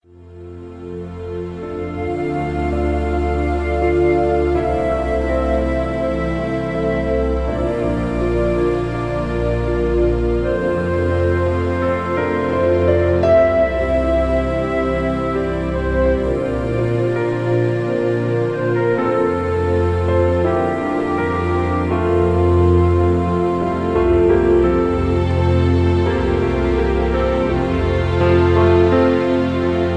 (Key-F)
Just Plain & Simply "GREAT MUSIC" (No Lyrics).